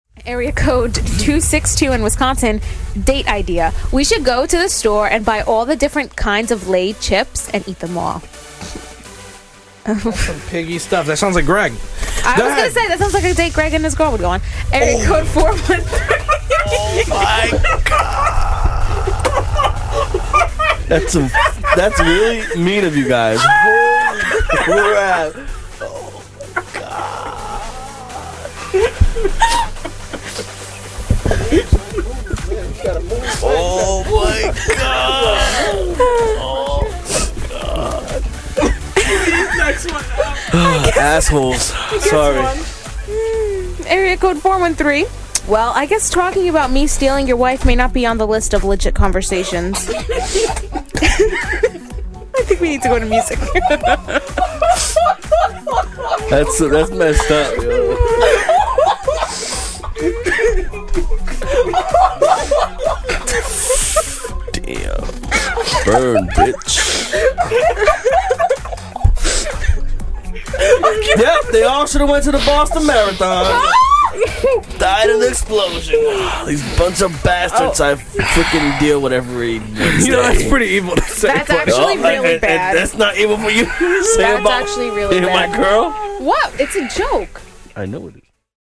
i can't stop laughing at us just dying at that joke.